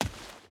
Dirt Run 5.ogg